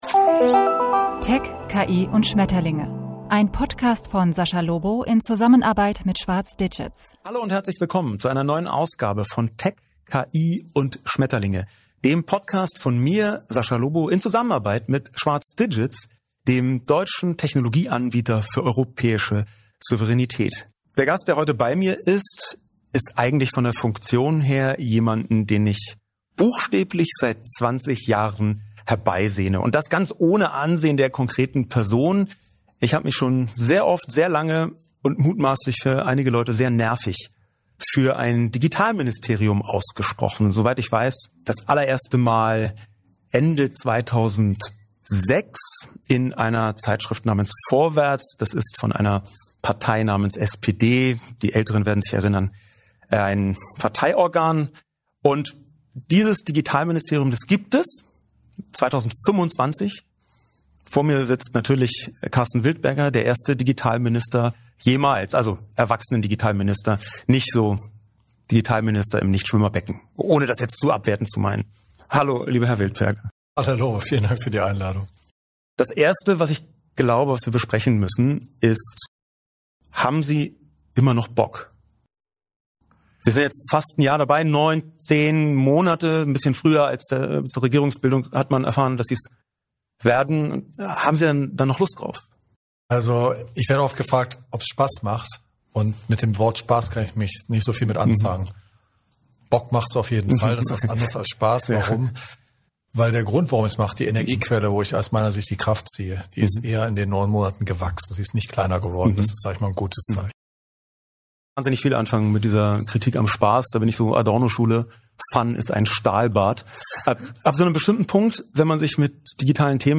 Deutschland zwischen Weltmarkt und Warteschleife: Im Gespräch mit Sascha Lobo erklärt Digitalminister Karsten Wildberger, warum digitale Souveränität mehr ist als ein geopolitisches Schlagwort – und warum sie über wirtschaftliche Stärke, demokratische Selbstbestimmung und gesellschaftliche Freiheit...